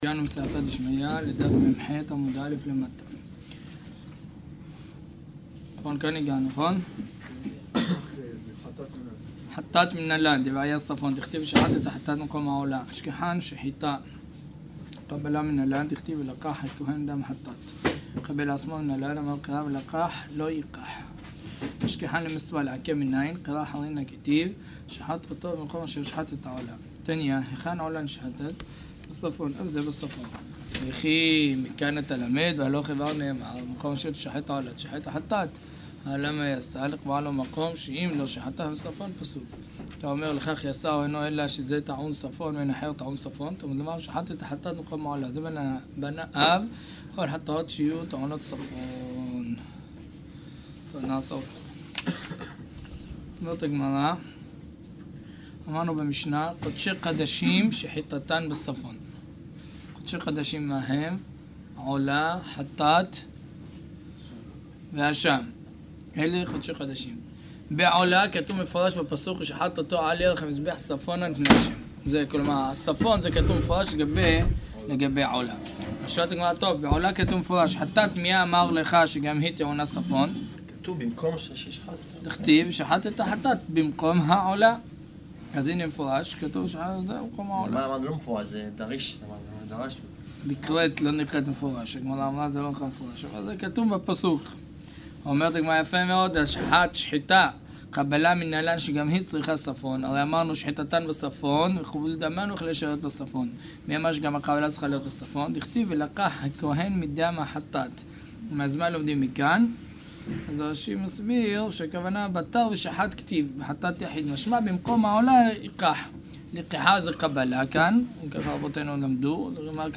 סדרת שעורי דף היומי מסכת זבחים. עם נגיעות בשיטת העיון הספרדית השיעור מתחיל בלימוד משנה